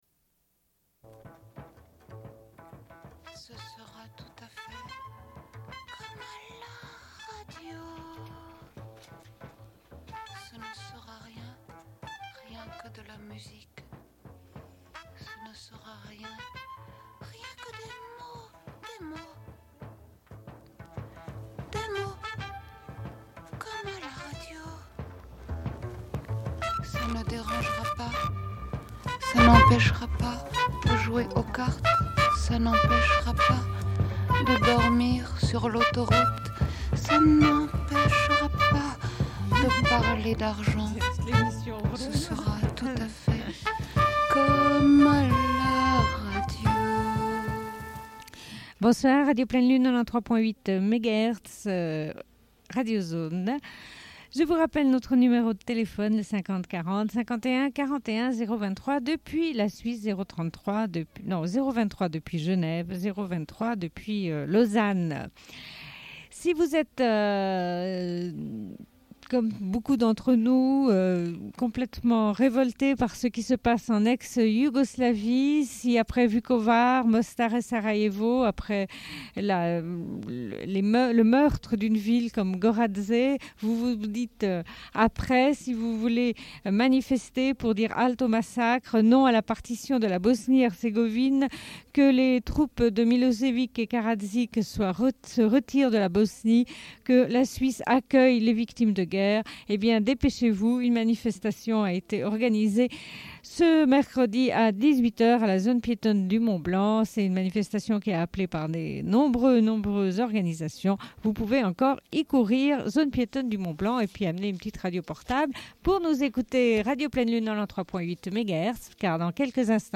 Bulletin d'information de Radio Pleine Lune du 27.04.1994 - Archives contestataires
Une cassette audio, face B28:57